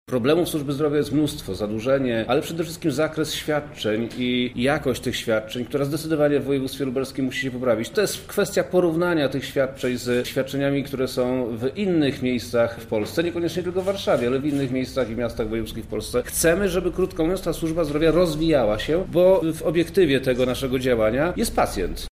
O sytuacji służby zdrowia na Lubelszczyźnie mówi wojewoda Przemysław Czarnek: